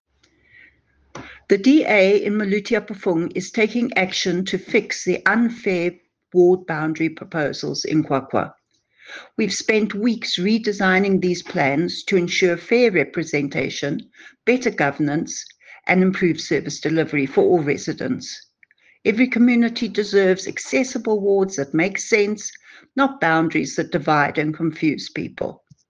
English soundbite by Cllr Alison Oates,